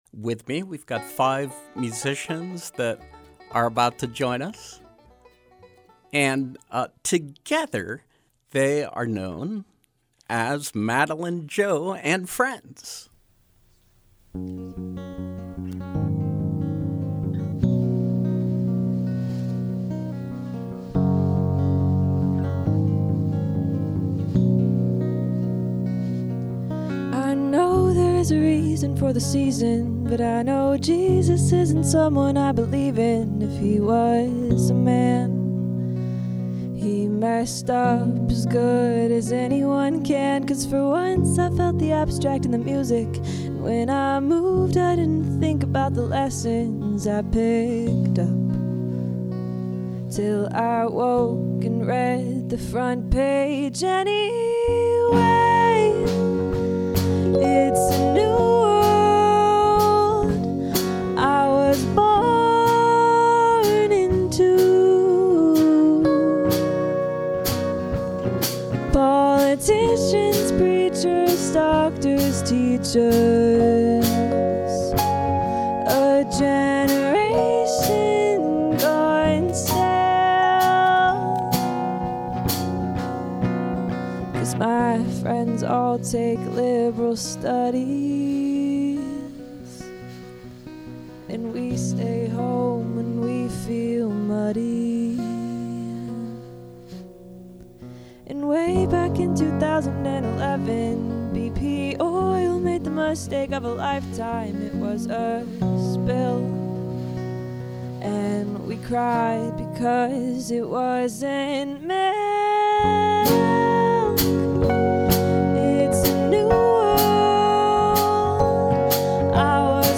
vocals
bass
keys
drums
guitar